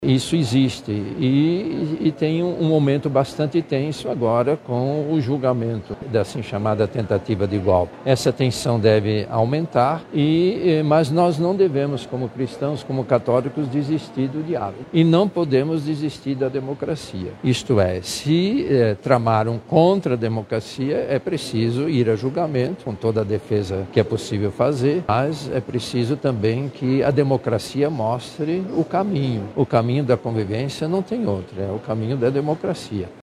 Durante uma recente declaração na Coletiva de Imprensa do Grito dos Excluídos 2025 em Manaus, o Cardeal Dom Leonardo Ulrich Steiner reforçou a importância da democracia como base para a convivência social e reafirmou a confiança nas instituições brasileiras.